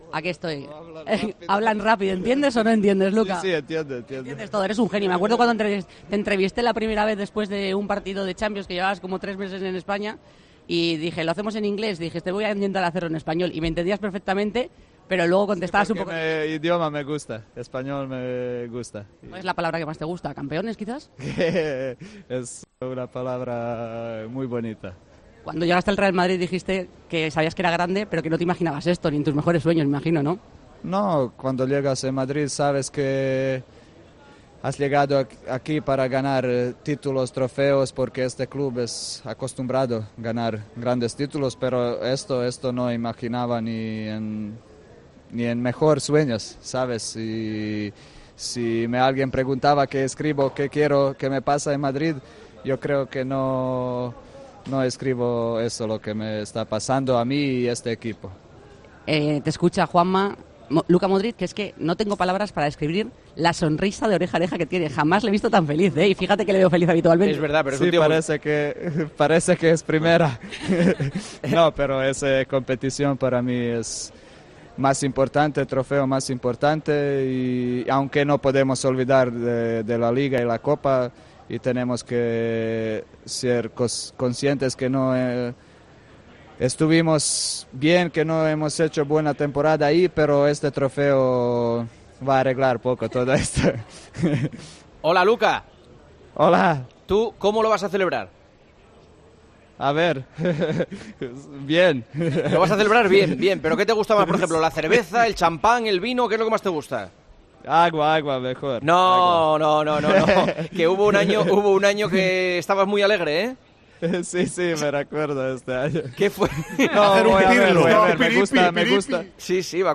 Un sonriente Luka Modric habló de su emoción en el inalámbrico